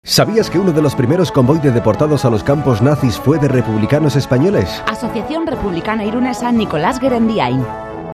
Maiatzan "Irun Irratian" emango dituzten iragarki laburrak